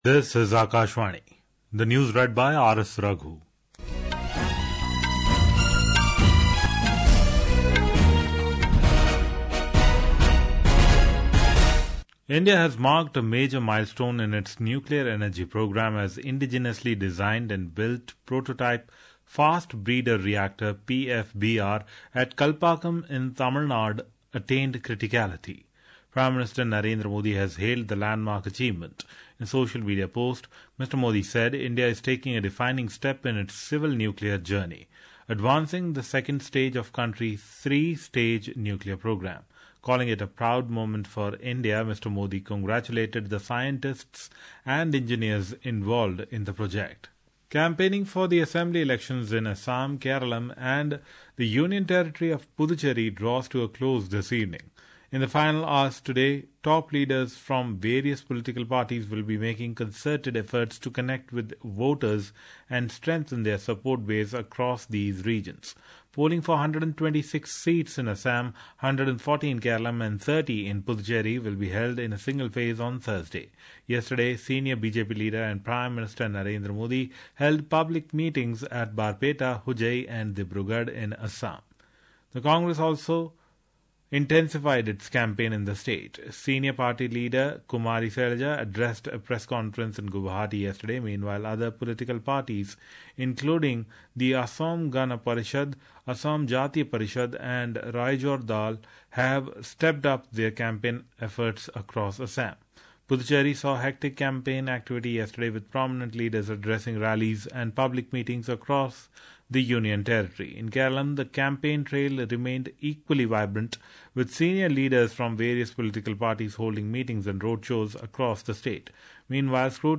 રાષ્ટ્રીય બુલેટિન
प्रति घंटा समाचार